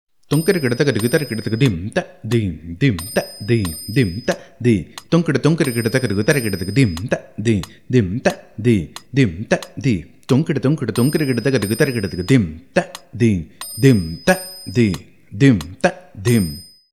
This is a mukthayam of 24 beats, which is a combination of both chaturashra nade and trishra nade.
Konnakol